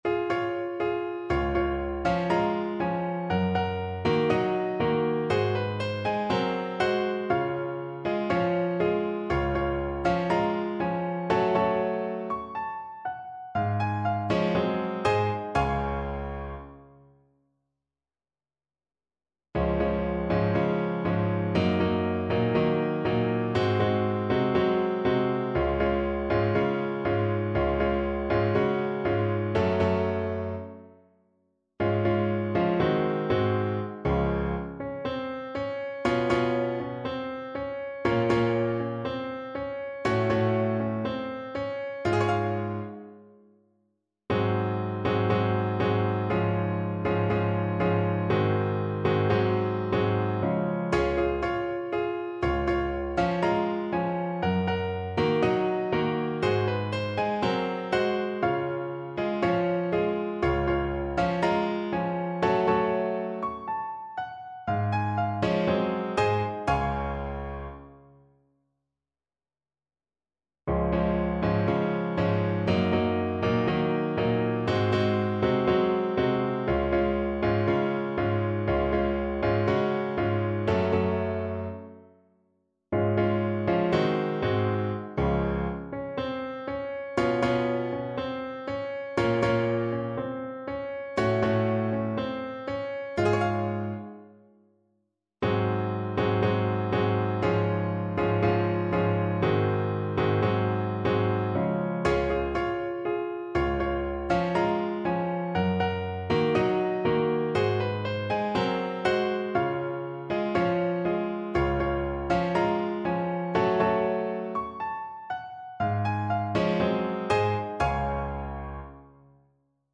Gracioso = 60
4/4 (View more 4/4 Music)